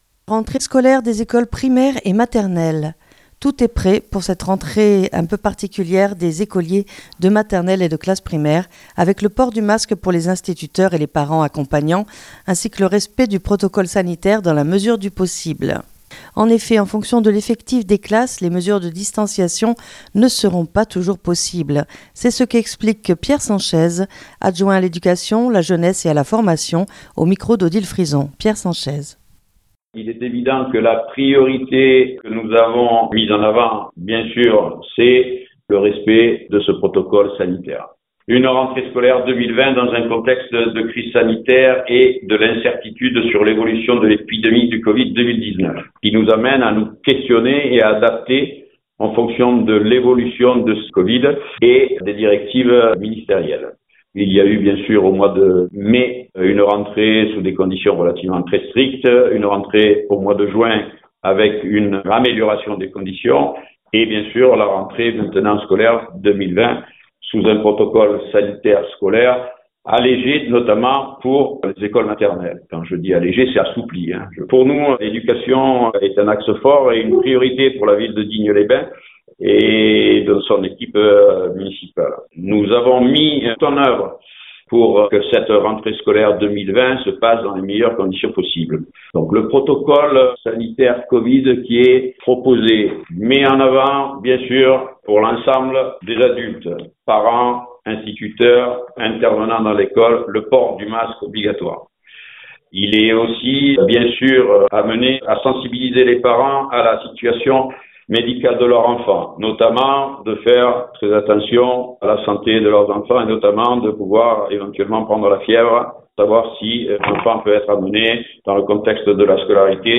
En effet, en fonction de l'effectif des classes, les mesures de distanciations ne seront pas toujours possibles. C'est ce qu'explique Pierre Sanchez Adjoint à l'éducation, la jeunesse et à la formation